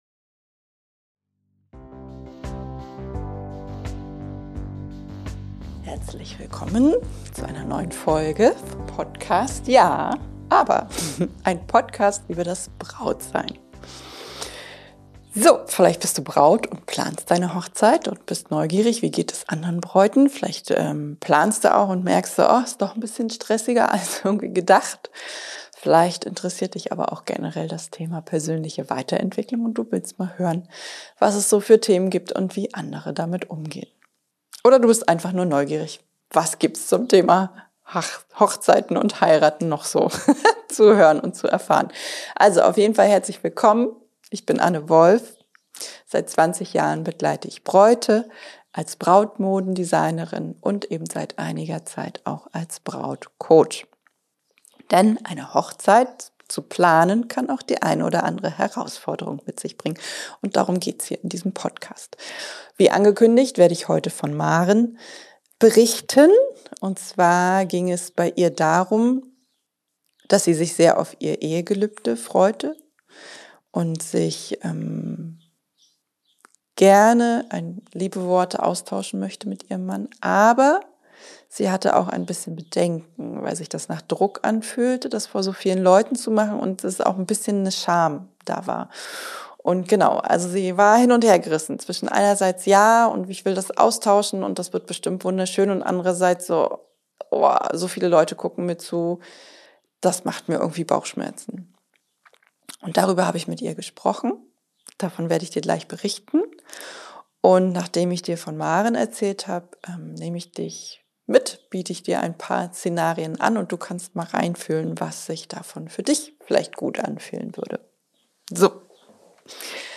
- Welche kreativen Alternativen es gibt, um sich trotzdem emotional auszudrücken? Und warum dein „Ja“ nicht laut sein muss, um echt zu sein Außerdem: 7 Ideen für ein persönliches Eheversprechen – ganz ohne Mikrofon. Interviewgast